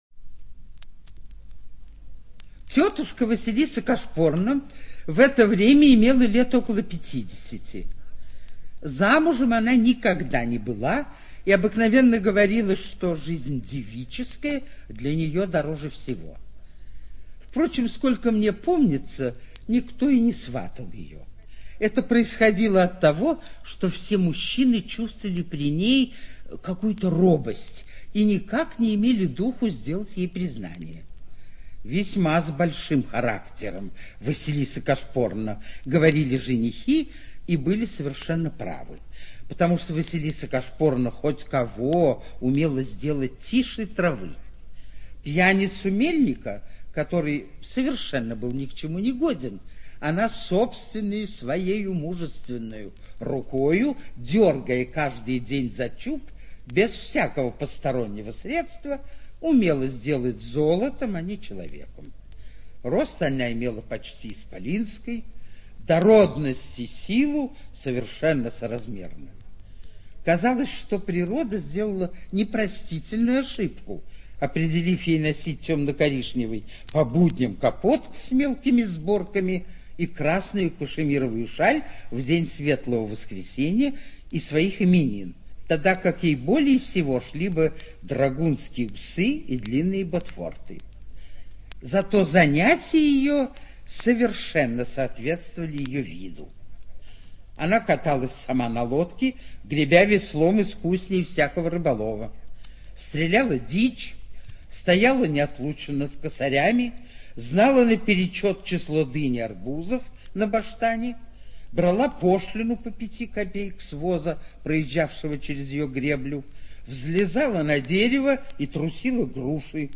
ЧитаетПашенная В.